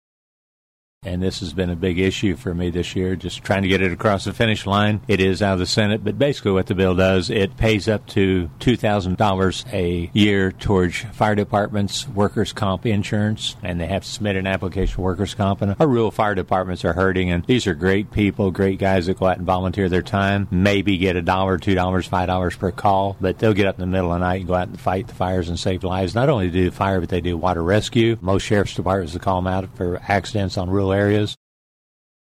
The following cuts are culled from the above interview with Sen. Cunningham, for the week of April 25, 2016.